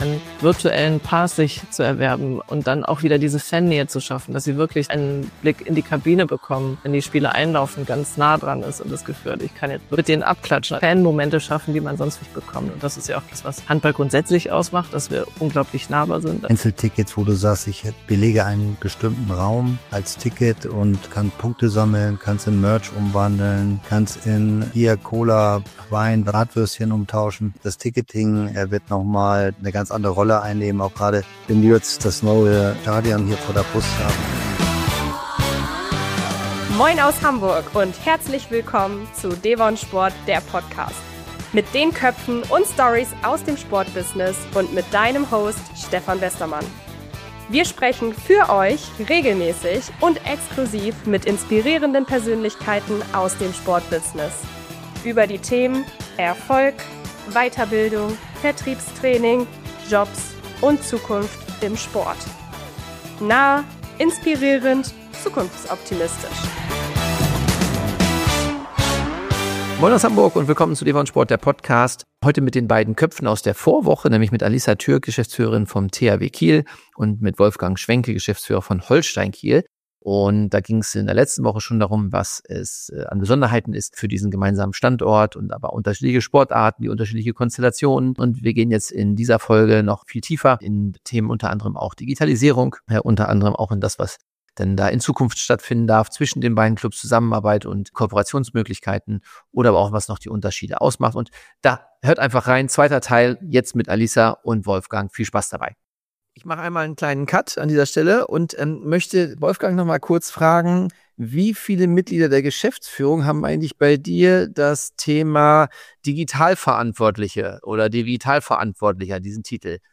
Wenn Handball und Fußball über KI, VR und Fanbegeisterung sprechen – entsteht ein Gespräch, das zeigt, wie viel Zukunft schon heute in Kiel steckt.